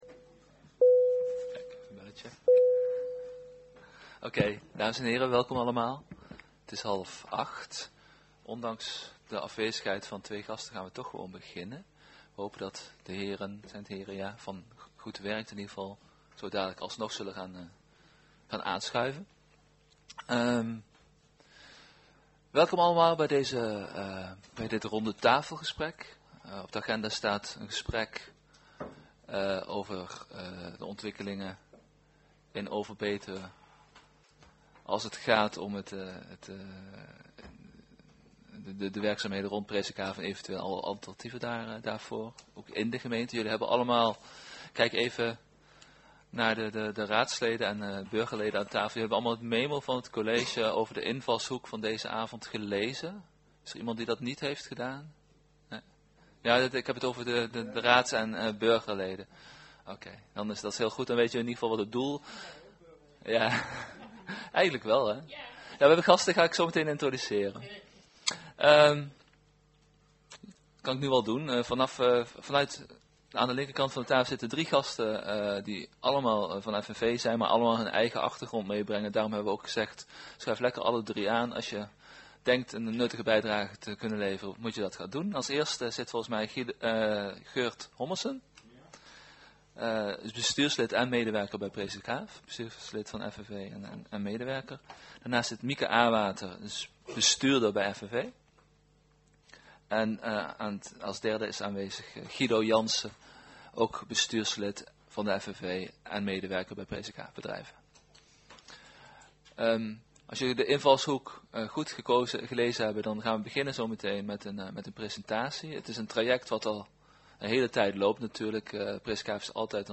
Commissiekamer, gemeentehuis Elst